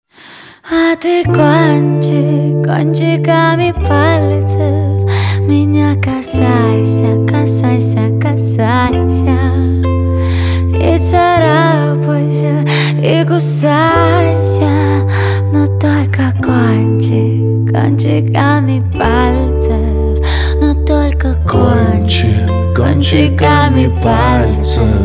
• Качество: 256, Stereo
поп
спокойные
дуэт
нежные